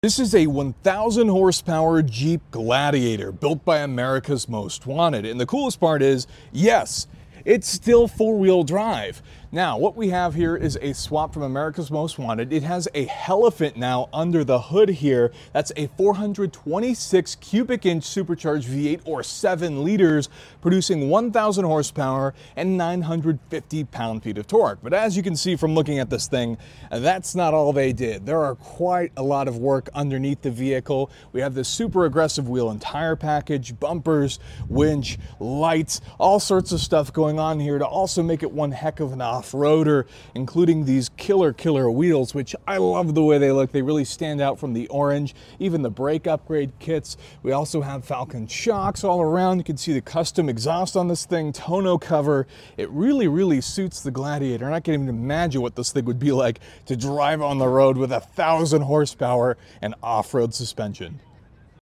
1,000hp Hemi Powered Jeep Gladiator